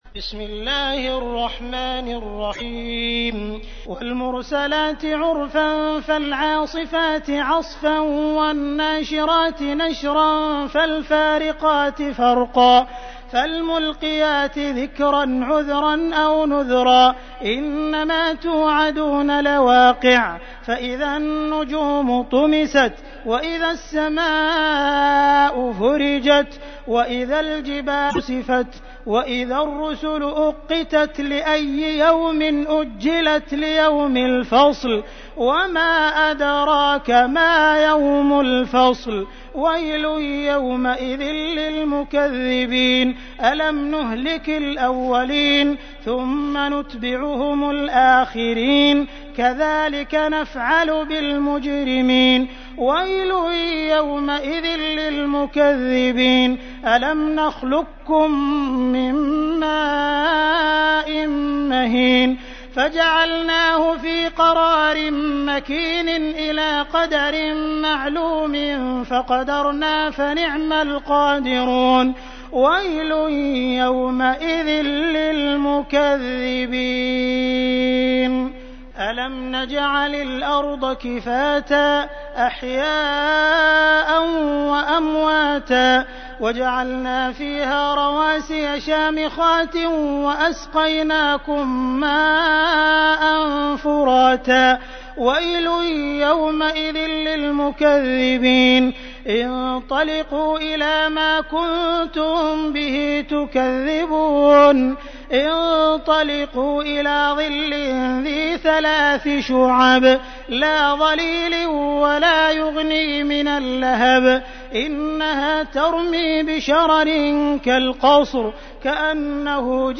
تحميل : 77. سورة المرسلات / القارئ عبد الرحمن السديس / القرآن الكريم / موقع يا حسين